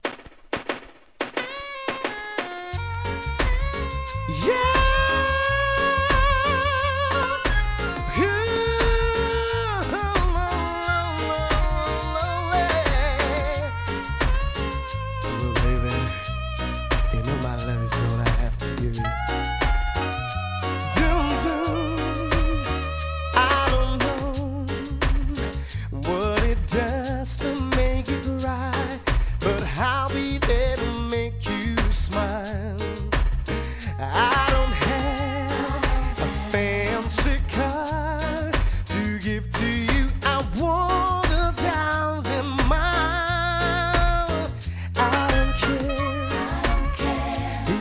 HIP HOP/R&B
素晴らしいヴォーカルで聴かせるNICEミディアム♪